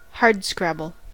hardscrabble: Wikimedia Commons US English Pronunciations
En-us-hardscrabble.WAV